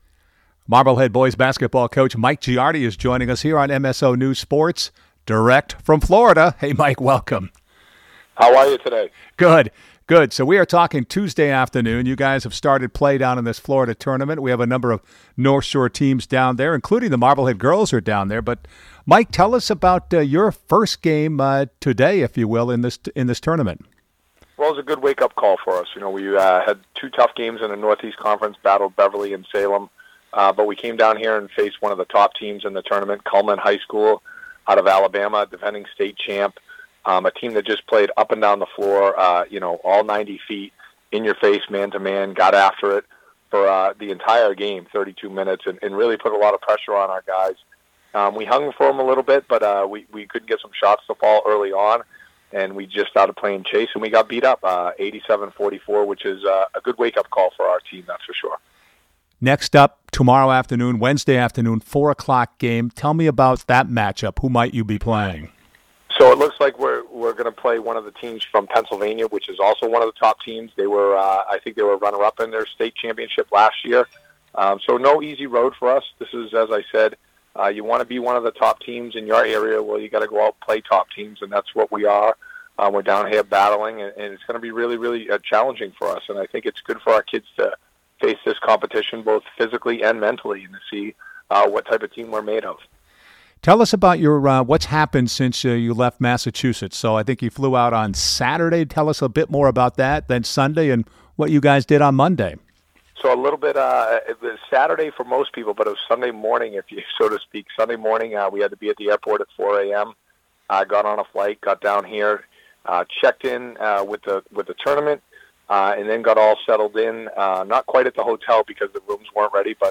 Wednesday Night Updated Interview – Marblehead boys lost today (Wednesday) Details in podcast